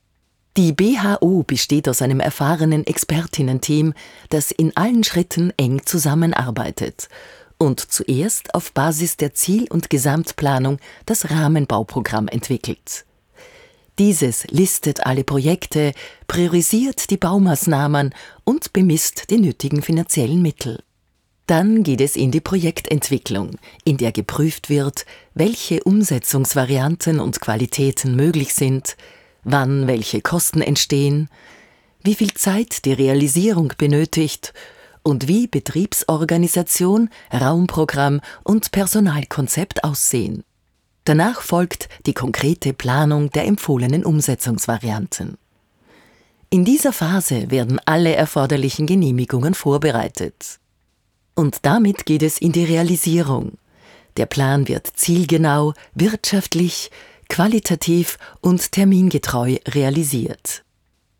Mittel plus (35-65)
Wienerisch
Presentation